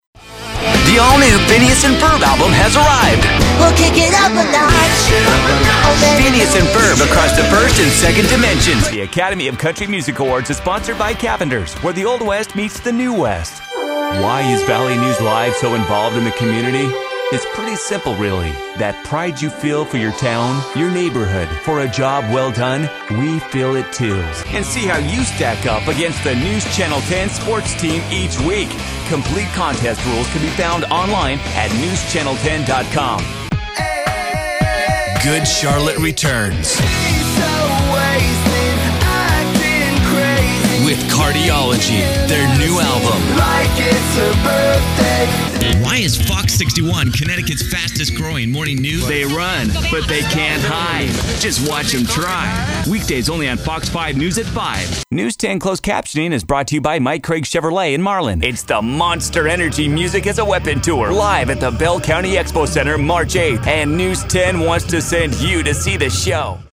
young, hip, cool, youthful, energetic,smooth, caring,fun,guy next door, announcer guy, excited, Television Imaging Voice, Radio Station Imaging Voice, Promo Voice Overs.
englisch (us)
Sprechprobe: Sonstiges (Muttersprache):